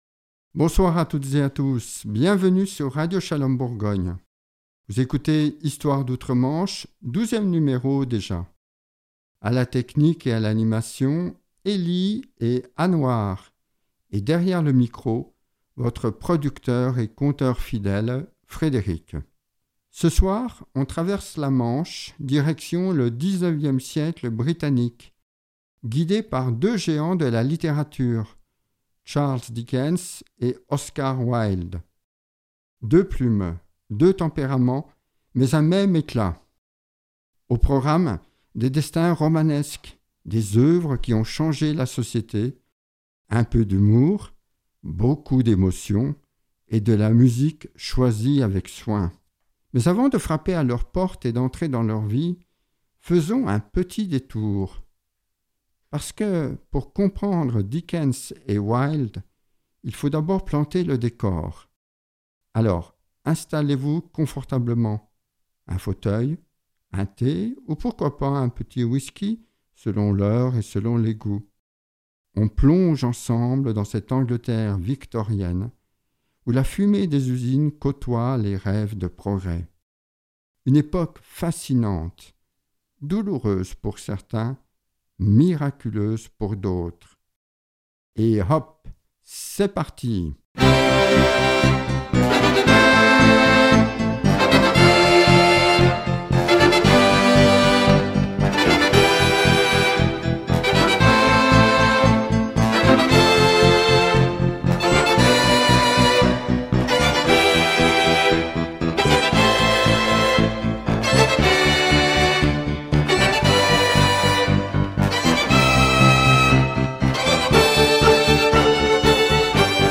Au programme : des destins romanesques,des œuvres qui ont changé la société, un peu d’humour, beaucoup d’émotion, et dela musique choisie avec soin.